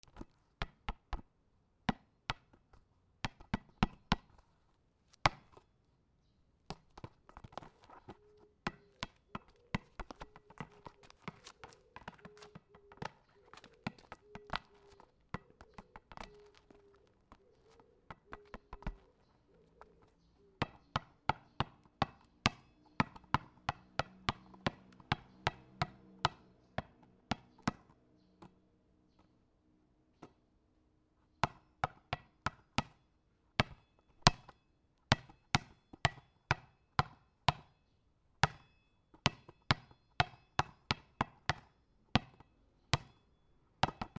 ゴムボール
/ G｜音を出すもの / Ｇ-15 おもちゃ